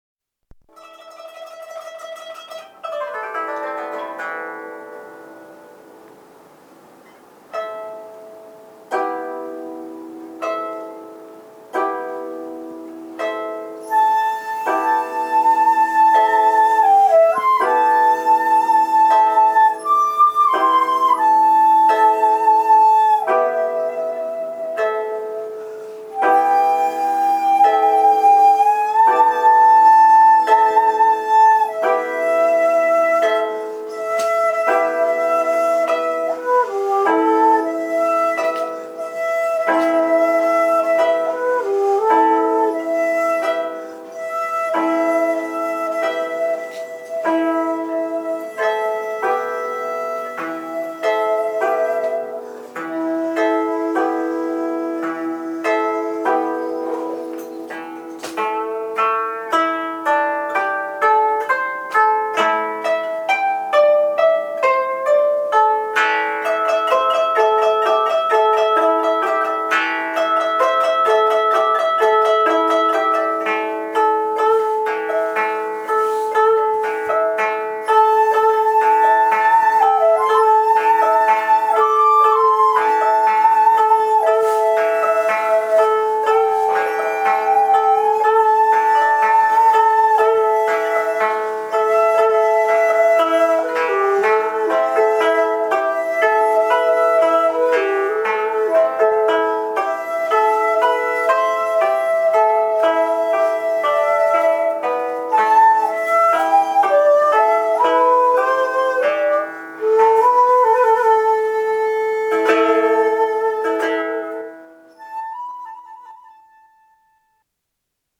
また「萌春」のほんの一部分ですが、ここの出だしはAm-Emの繰り返して正に西洋の和音そのものです。
この曲も純正調に合わせていますので少しお聴きください。